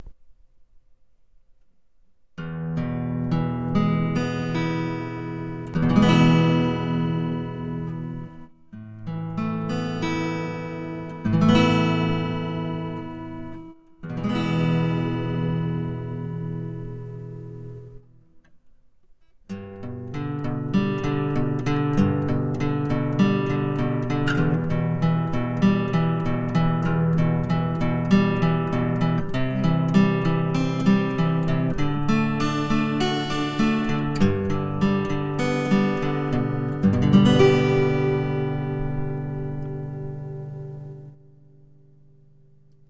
左側のスピーカーにマイク、右側のチャンネルにピエゾ　として、録音した音源↓
左がマイクー右がピエゾの音源
左のスピーカーに耳を近づけると、マイク録音の音、逆だとピエゾの音が聴けるはず。
ピックアップシステムが・・・とか、音の質が・・・というよりも、空気を伝わってくる音と表板の振動を拾った音。
この差による、リバーブ感の差だと思いますが・・・・どうでしょ。
LEFT_MIC_RIGHT_PIEZ.mp3